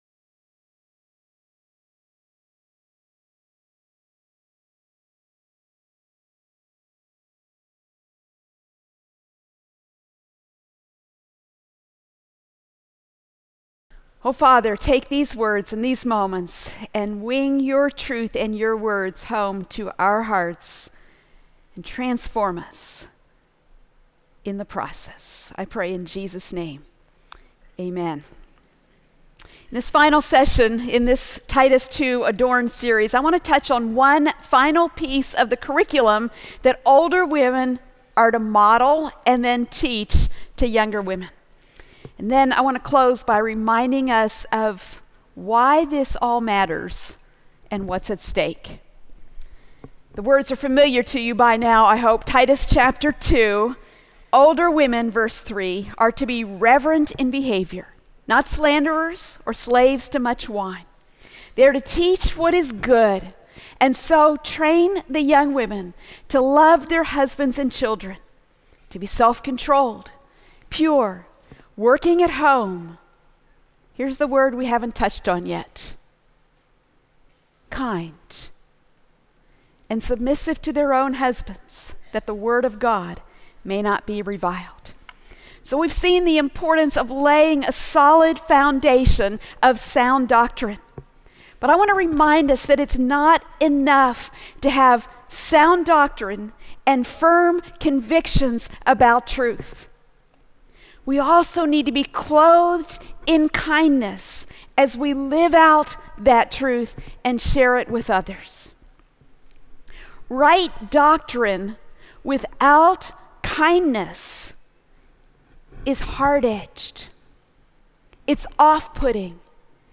She also wraps up the conference by discussing what is at stake if we don’t practice Titus 2 life-to-life discipleship.